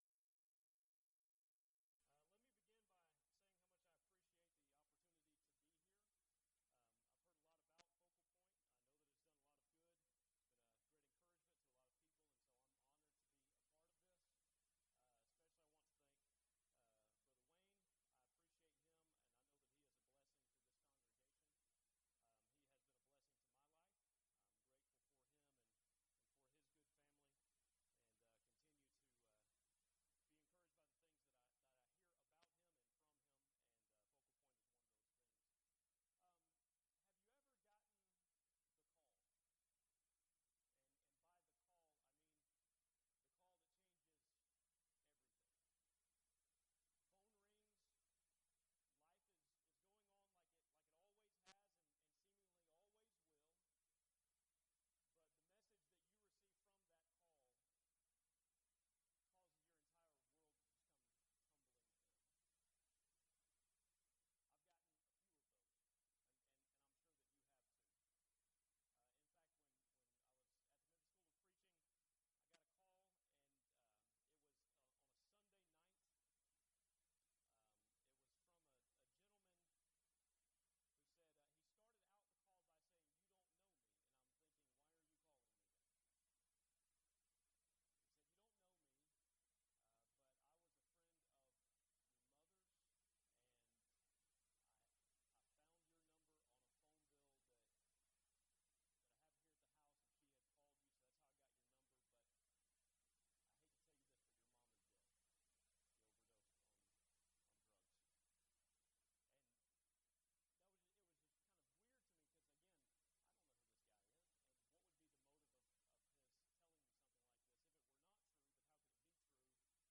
Event: 2017 Focal Point
lecture